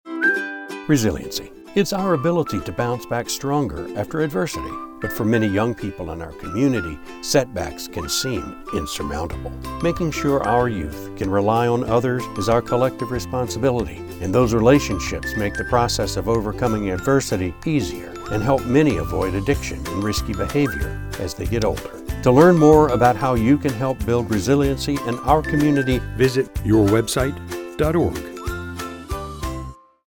30-second radio spot